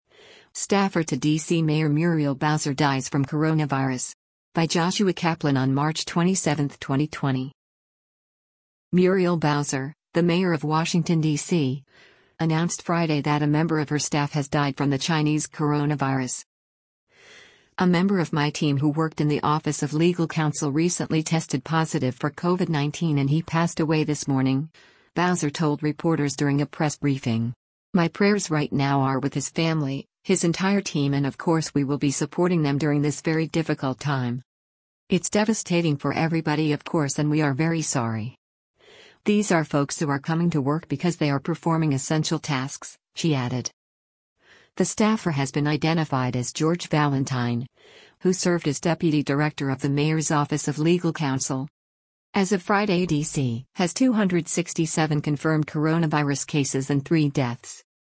“A member of my team who worked in the office of legal counsel recently tested positive for COVID-19 and he passed away this morning,” Bowser told reporters during a press briefing.